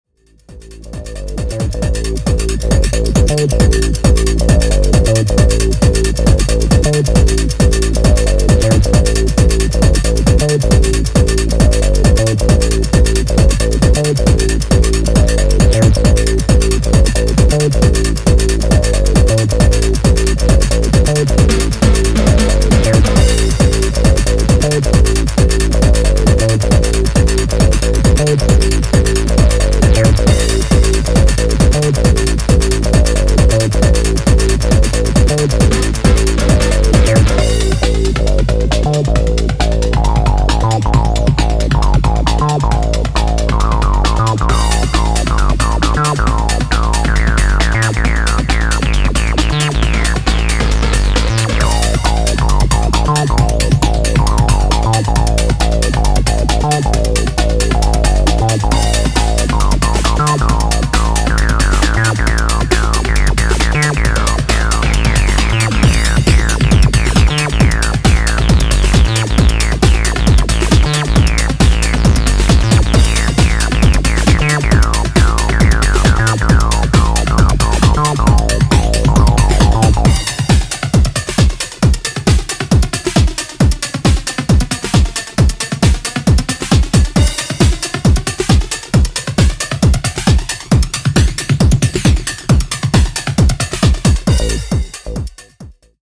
Genre Breakbeat , Tech House